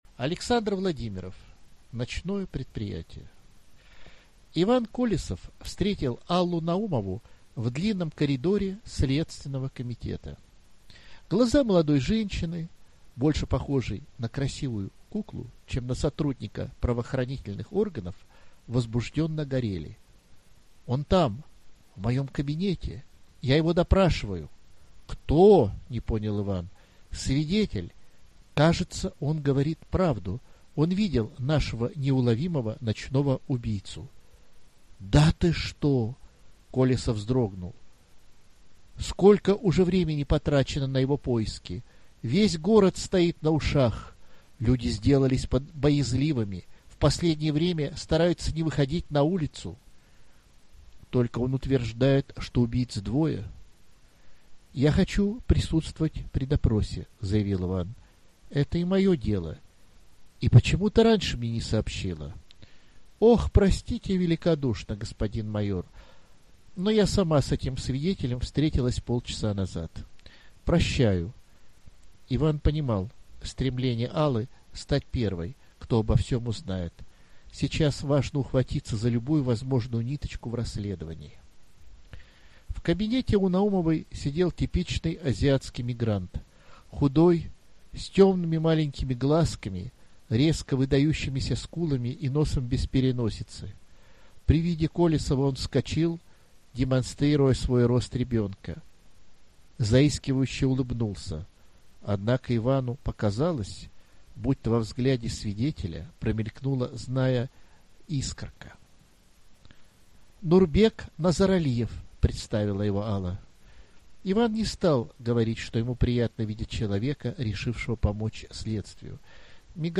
Аудиокнига Ночное предприятие | Библиотека аудиокниг